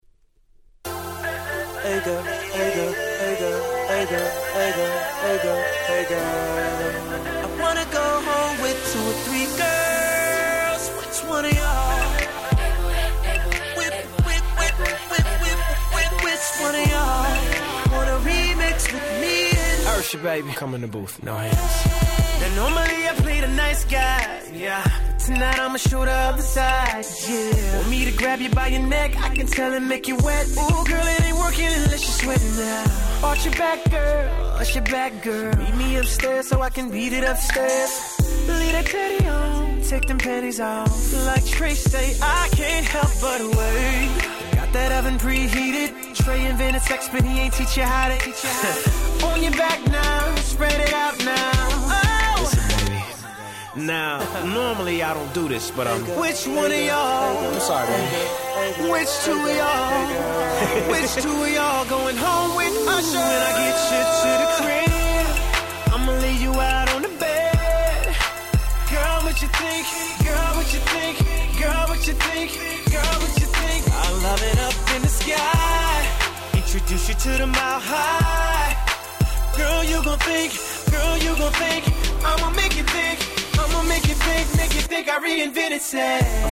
US Original Press.
09' Super Hit R&B !!